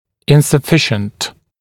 [ˌɪnsə’fɪʃnt][ˌинсэ’фишнт]недостаточный; несоответствующий, неподходящий